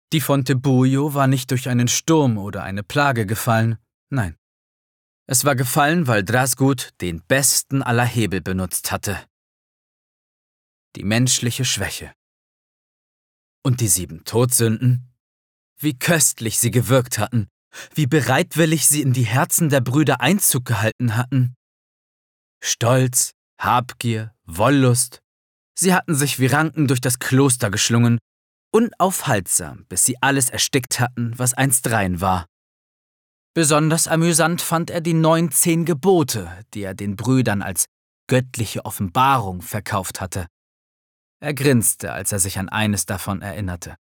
Erzählerisch-dämonische Stimme mit schelmischem Unterton und dunkler Ironie.
Hörbuch